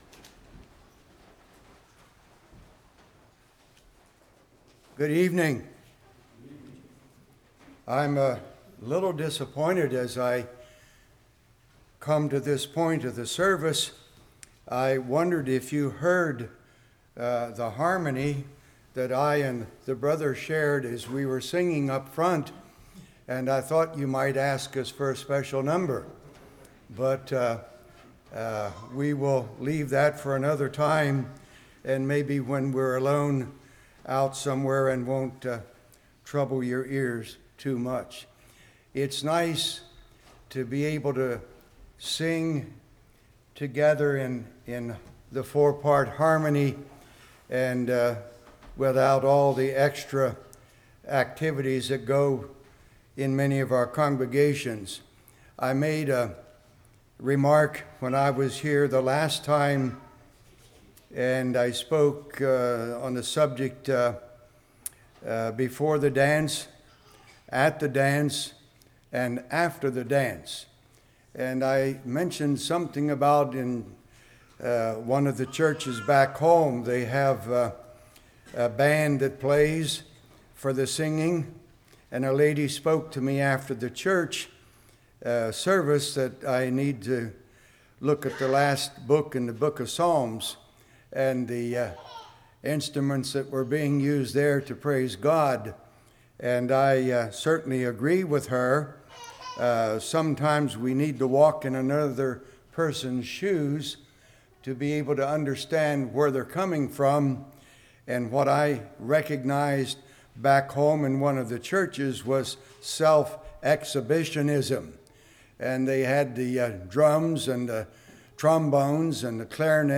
Spring Revival 2013 Passage: Matthew 21:1-10 Service Type: Revival Steadfastly Joyfully Hopefully « The Guilty World